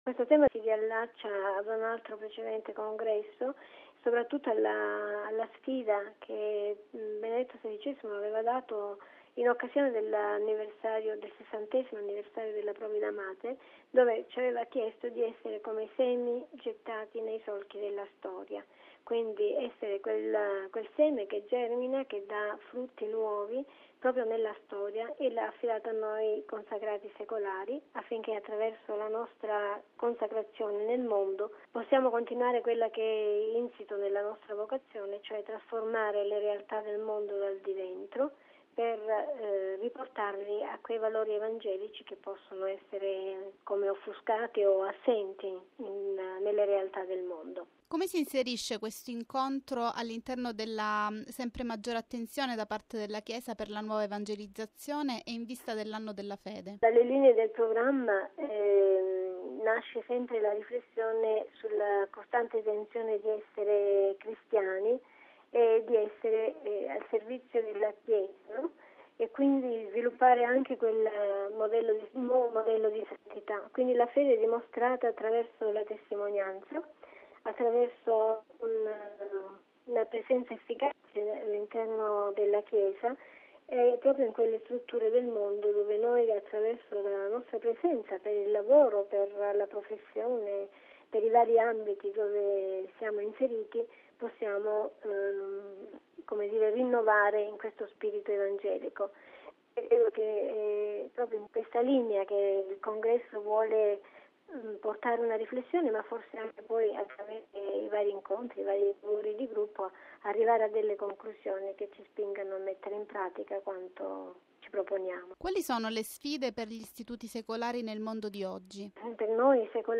raggiunto telefonicamente al Congresso di Assisi